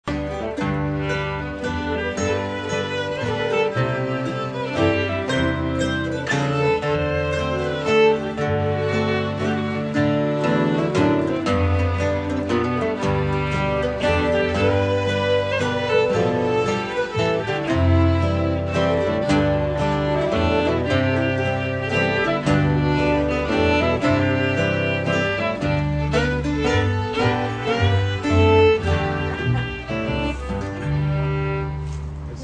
Form: Waltz?*
Most sources show this tune in A major but the MP3 clip above is played in G.